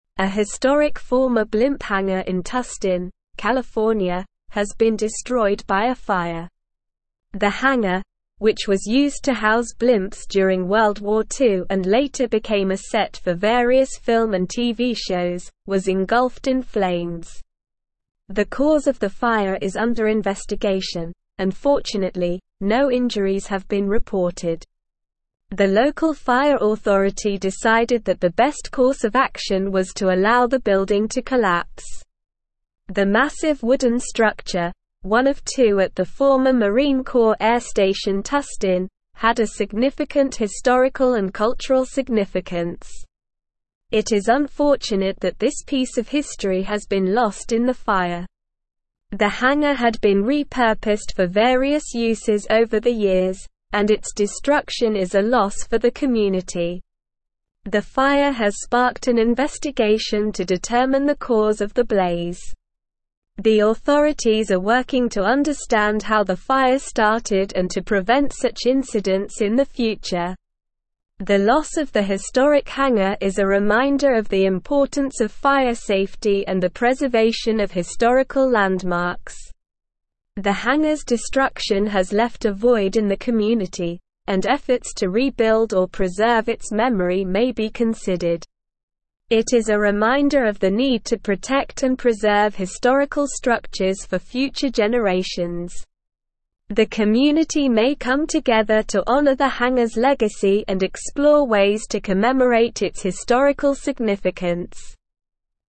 Slow
English-Newsroom-Advanced-SLOW-Reading-Historic-World-War-Two-Era-Blimp-Hangar-Engulfed-in-Flames.mp3